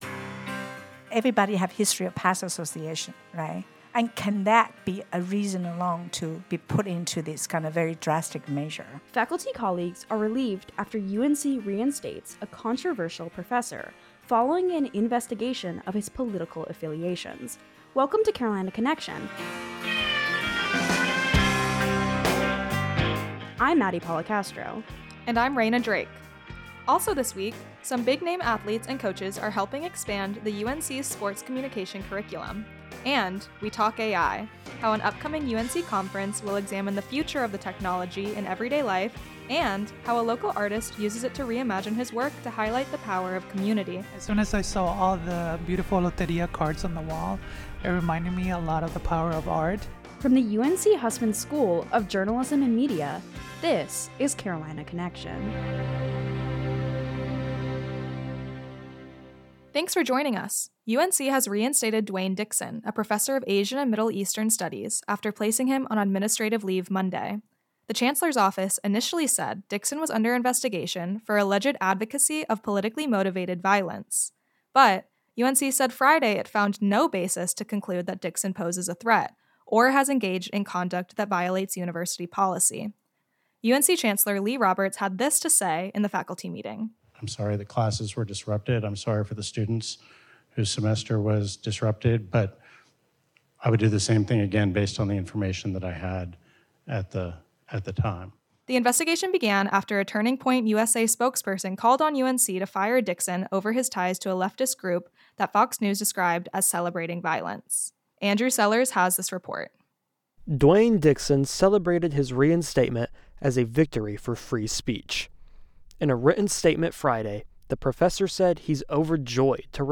The student-produced newsmagazine from the University of North Carolina Hussman School of Journalism and Media